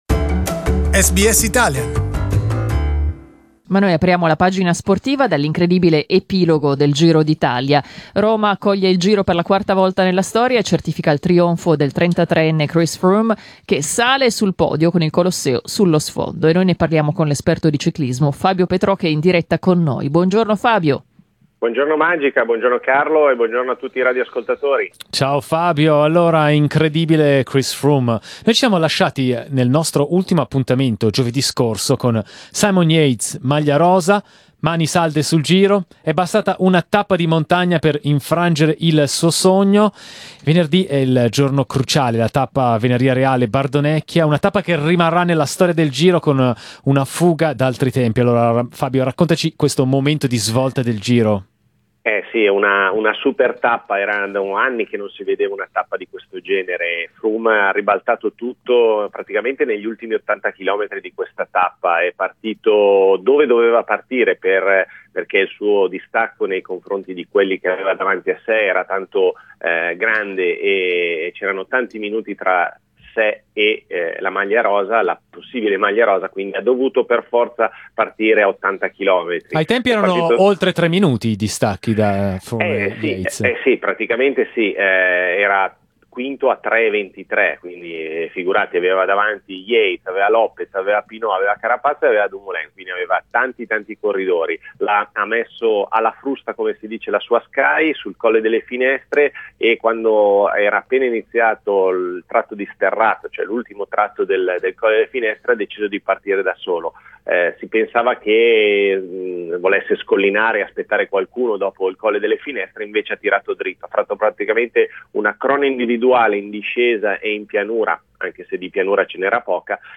Cycling analyst